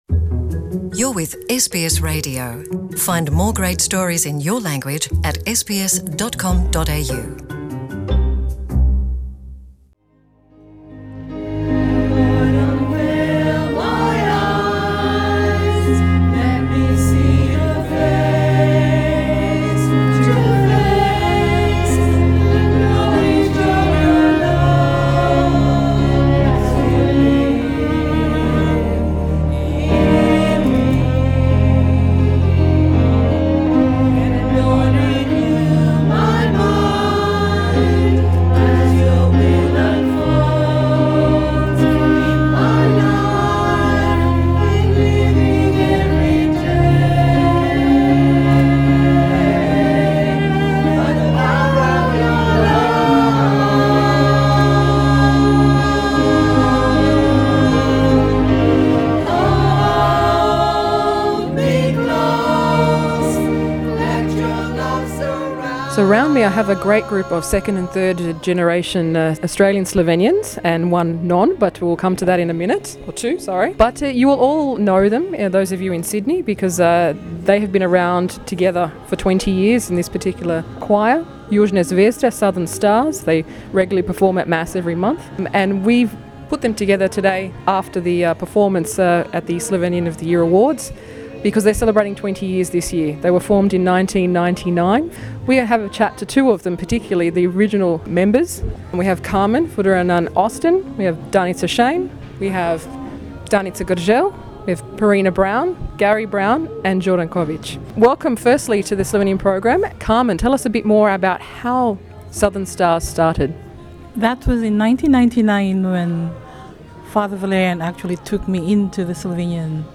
Mešani pevski zbor Južne zvezde letos praznuje 20 let delovanja. Pogovarjali smo se z člani, ki so druge in tretje generacije avstralskih slovencev, razen dva ki imata globoke vezi s skupnostjo v Sydneyu, o začetki zbora in kaj jih drži skupaj še danes.